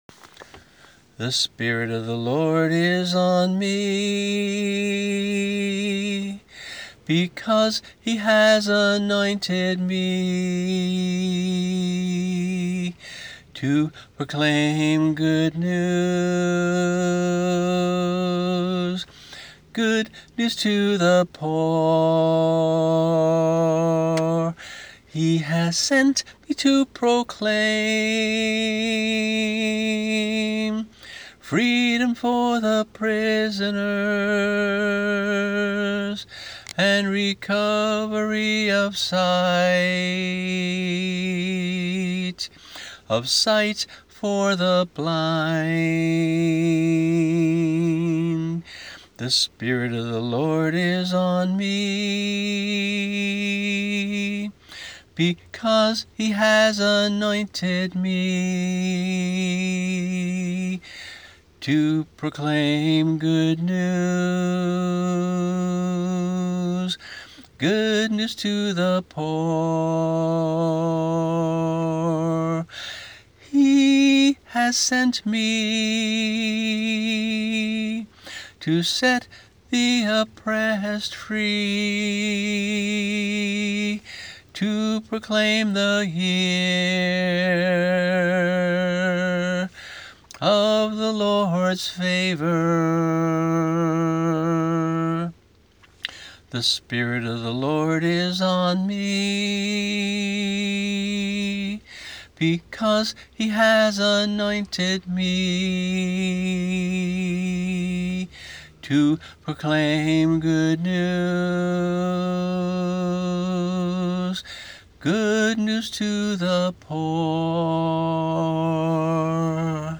[MP3 - voice only]
Luke 4:18-19 (NIV),  a 2-part echo song